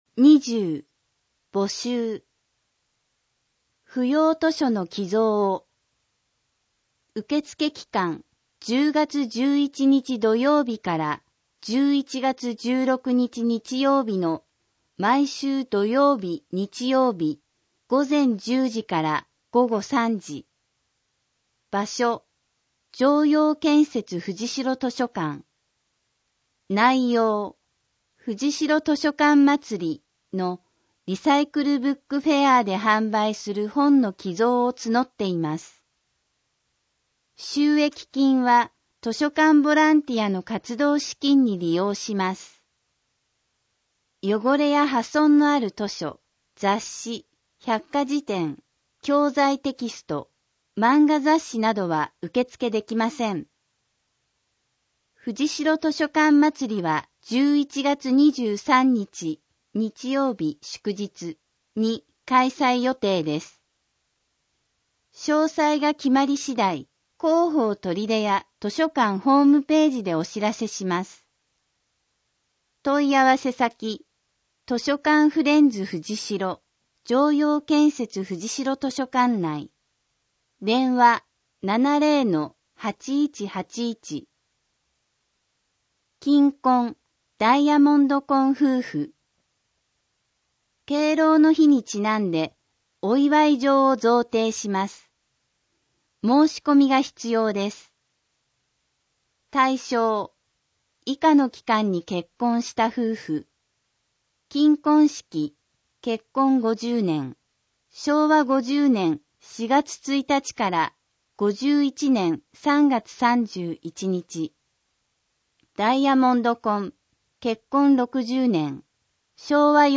取手市の市報「広報とりで」2025年9月15日号の内容を音声で聞くことができます。音声データは市内のボランティア団体、取手朗読奉仕会「ぶんぶん」の皆さんのご協力により作成しています。